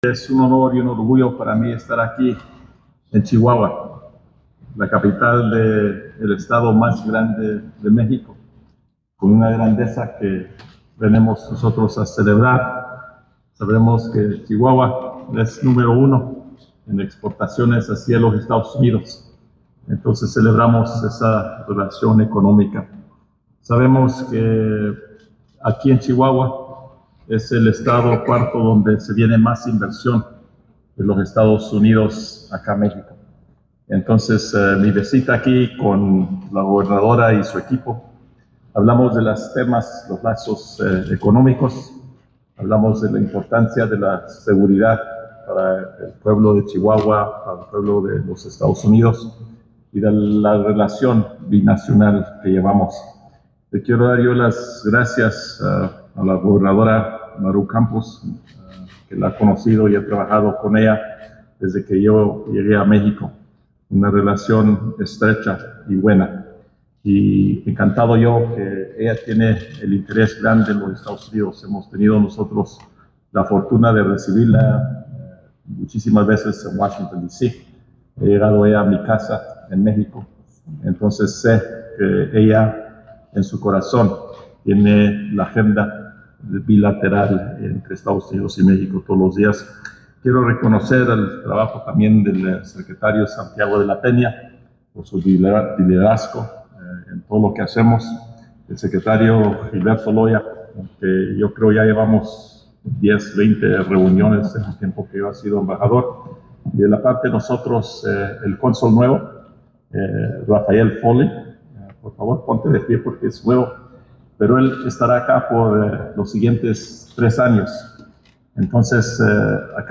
AUDIO; KEN SALAZAR, EMBAJADOR DE ESTADOS UNIDOS EN MÉXICO